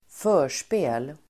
Uttal: [²f'ö:r_spe:l]